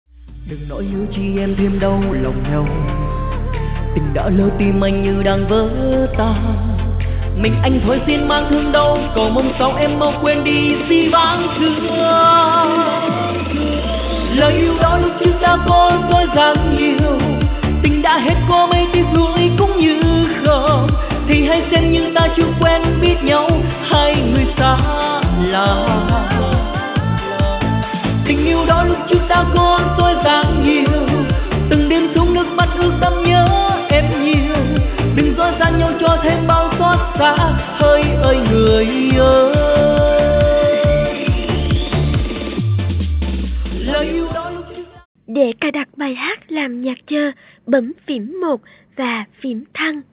EDM/ Underground